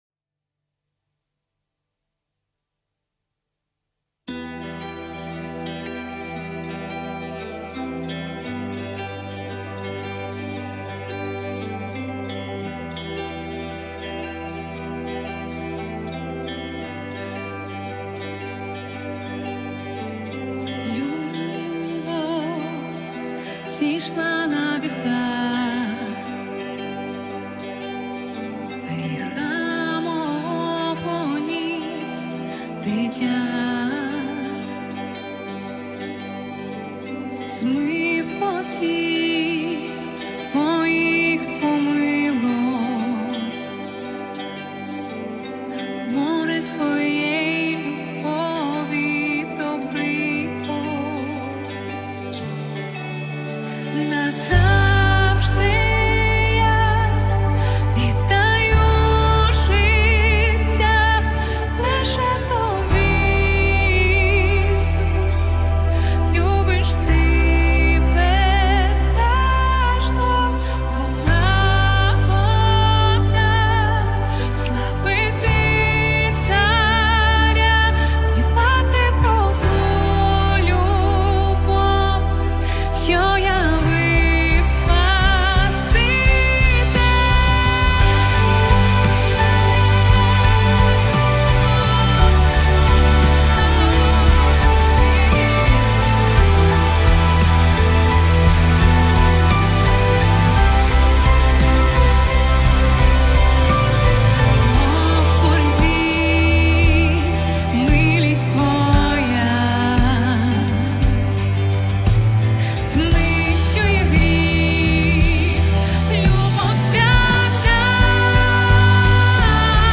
100 просмотров 92 прослушивания 6 скачиваний BPM: 86